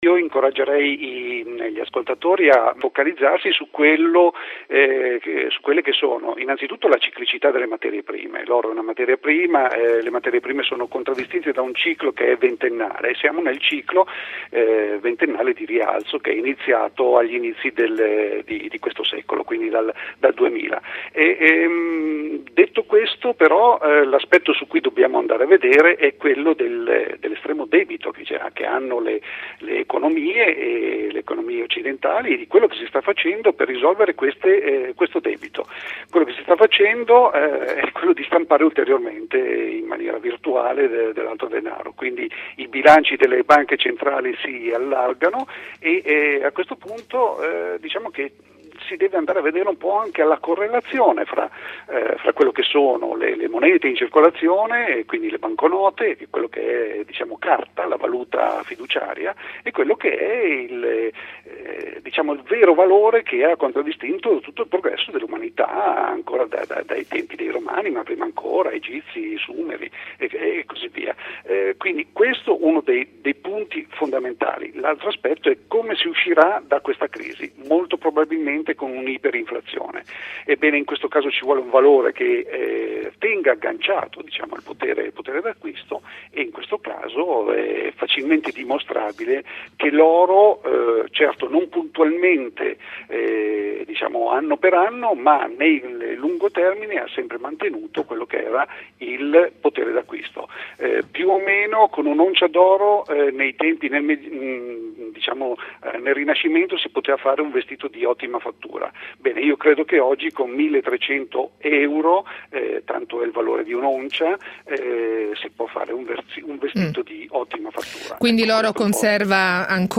Audiocommento del 23 ottobre – oro e inflazione